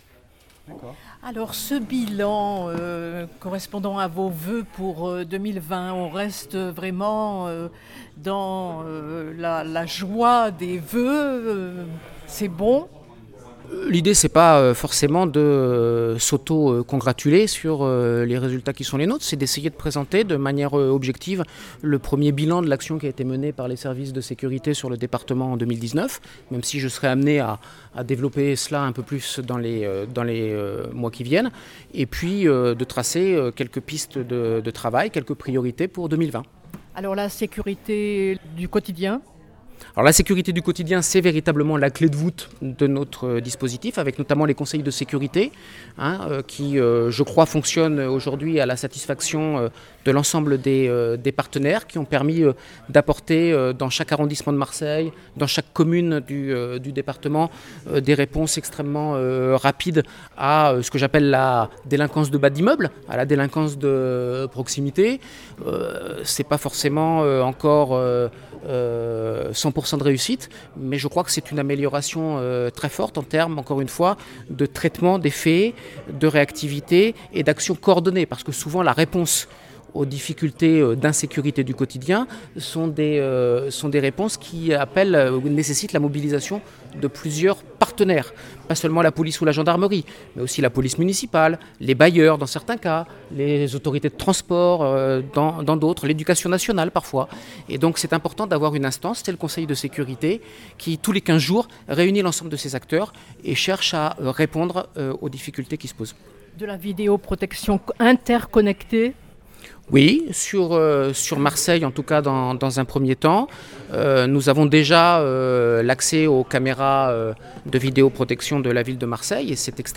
prefet_de_police_olivier_de_mazieres_8_01_2020.mp3